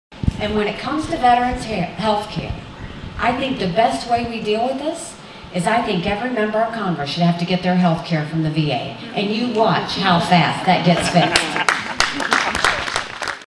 Republican Presidential Candidate Nikki Haley Makes Campaign Stop in Atlantic